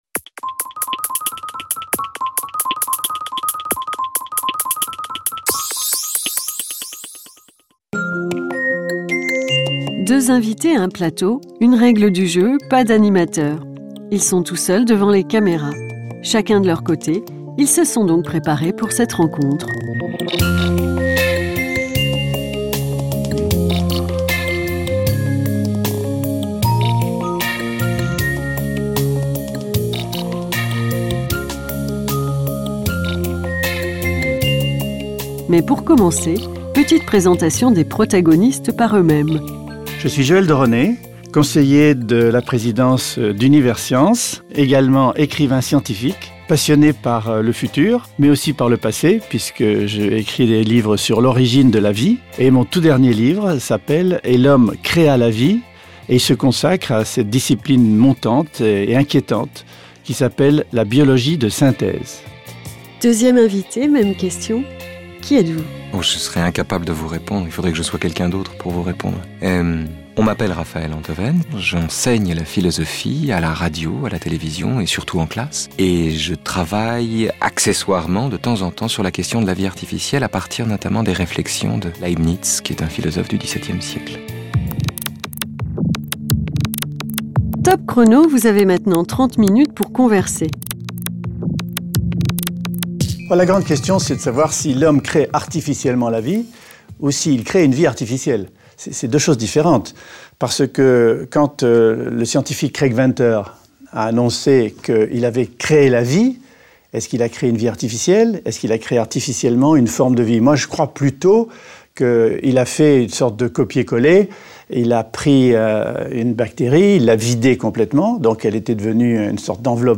Avec Raphaël Enthoven, philosophe, et Joël de Rosnay, biologiste.